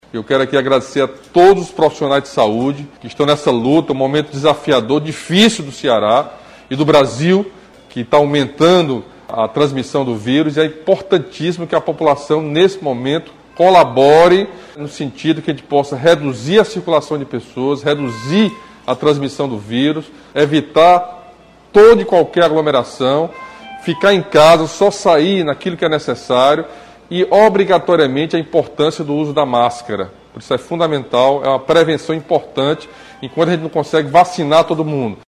O anúncio foi realizado nesta sexta-feira (26) durante transmissão ao vivo nas redes sociais.
O governador agradeceu o empenho dos profissionais de saúde na viabilização dos novos leitos de UTI e lembrou ainda que a população precisa manter os cuidados para evitar o aumento da contaminação.